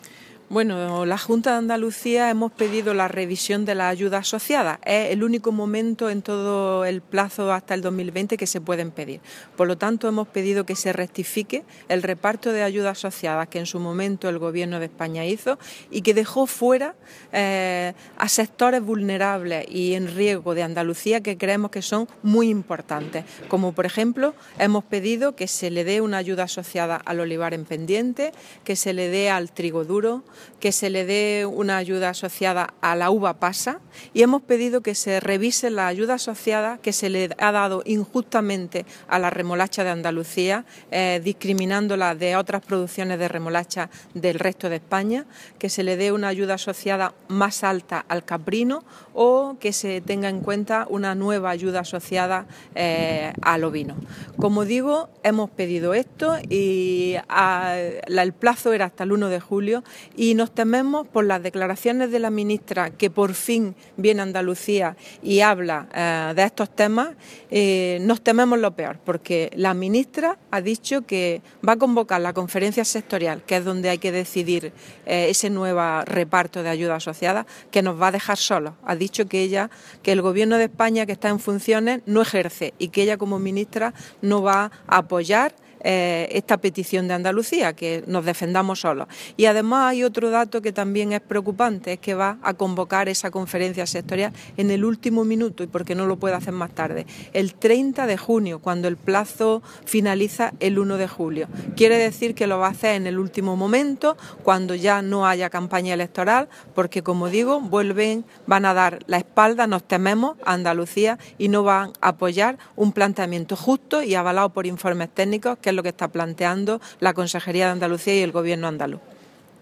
Carmen Ortiz durante la Comisión parlamentaria
Declaraciones de la consejera sobre ayudas asociada de la PAC